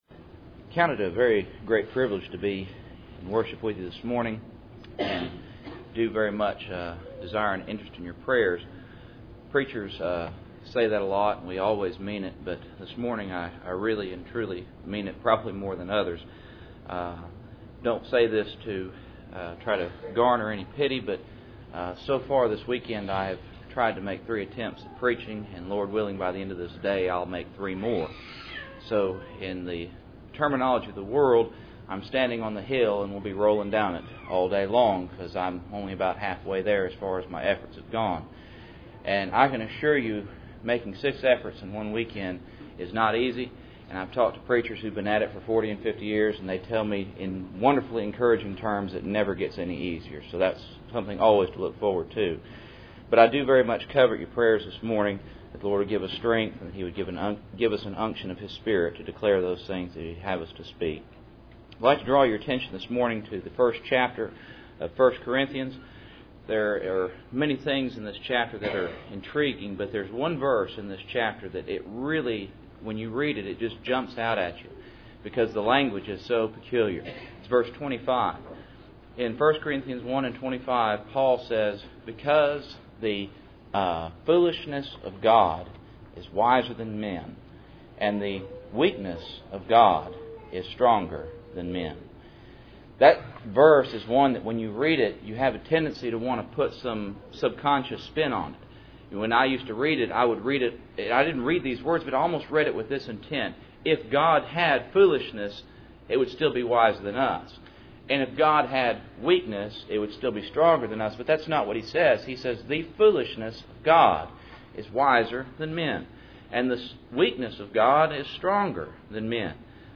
Passage: 1 Corinthians 1:25 Service Type: Cool Springs PBC Sunday Morning %todo_render% « Assurance and Obedience Rahab